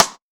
Chart rimshot 02.wav